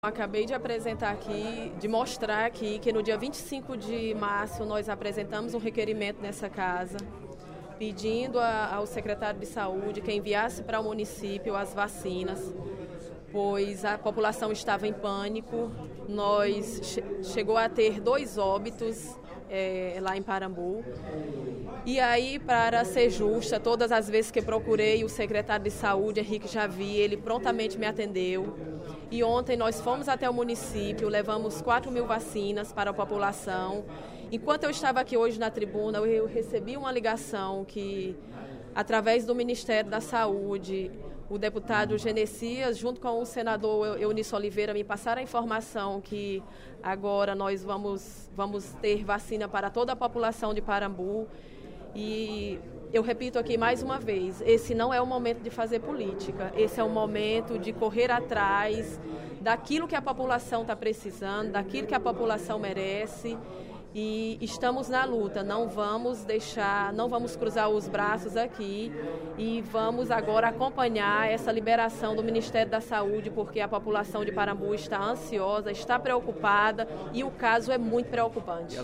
A deputada Aderlânia Noronha (SD) informou, durante o primeiro expediente da sessão plenária desta terça-feira (06/06), que o município de Parambu recebeu quatro mil vacinas para combater o surto de meningite no município.